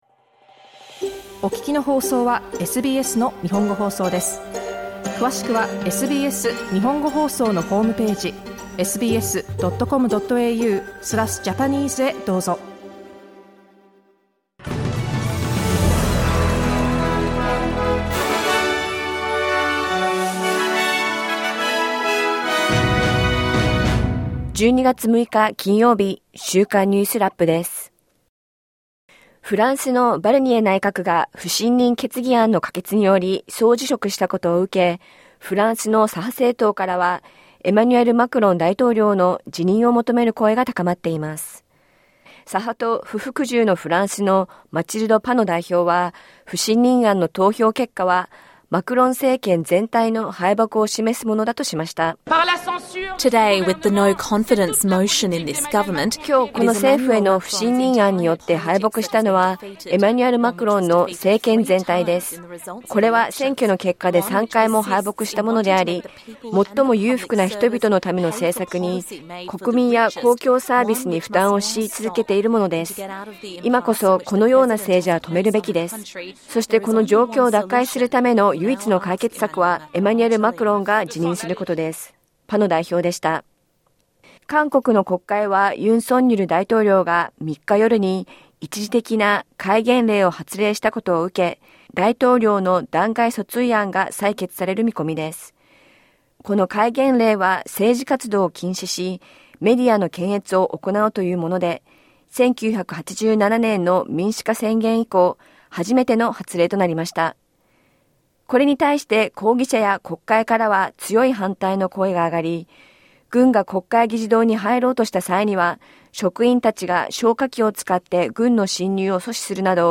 SBS日本語放送週間ニュースラップ 12月6日金曜日